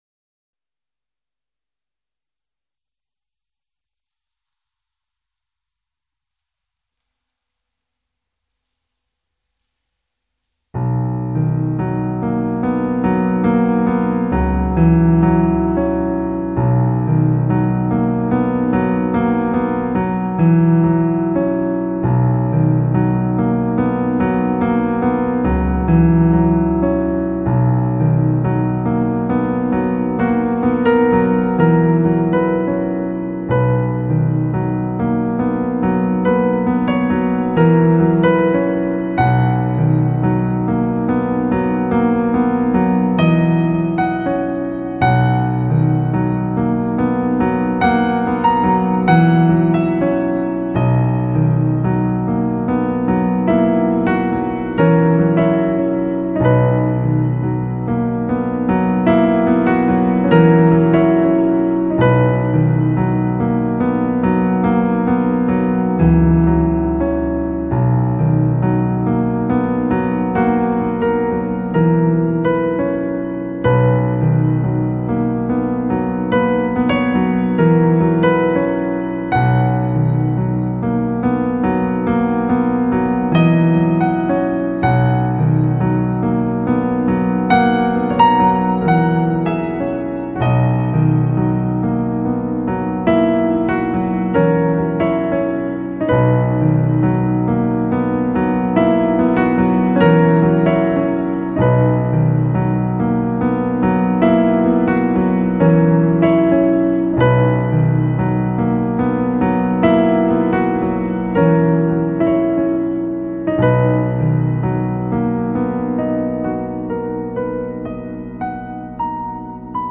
因此作品也洋溢着南洋海风温暖潮湿的气息